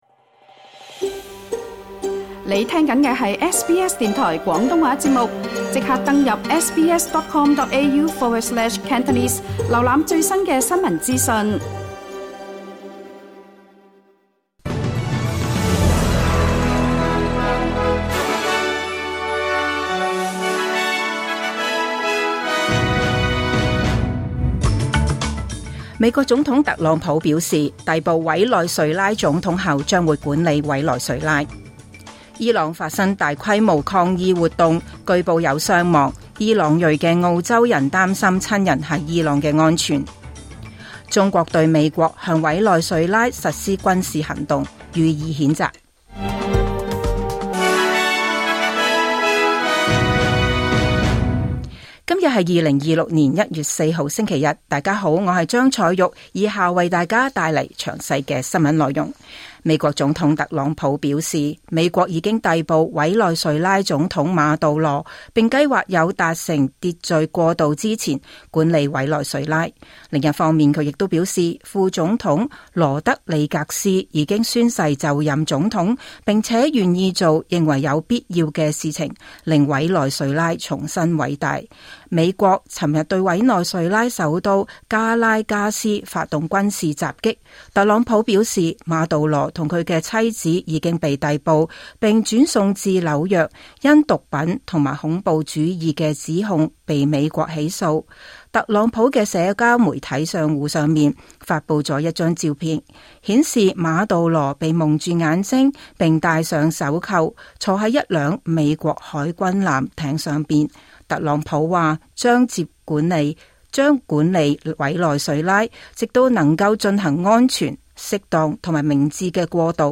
2026 年 1月 4 日 SBS 廣東話節目詳盡早晨新聞報道。